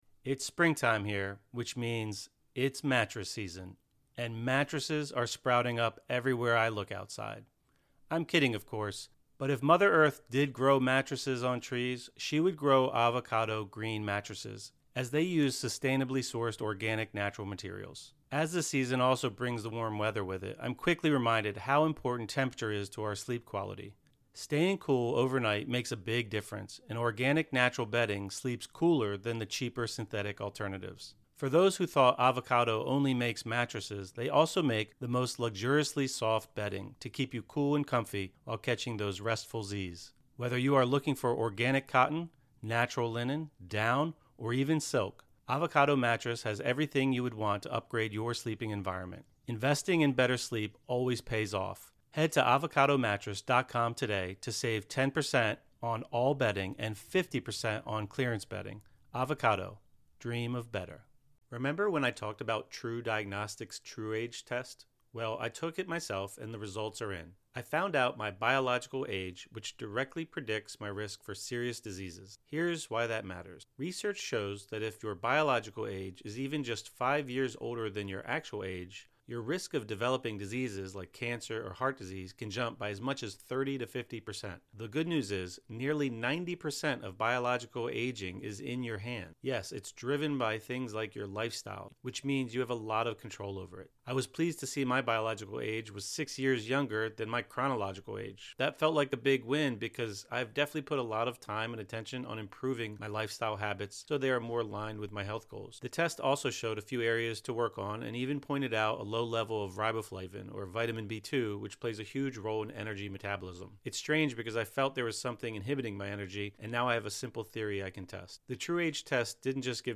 20 Minute Guided Meditation | Cultivate Forgiveness for Yourself and Others (; 04 May 2025) | Padverb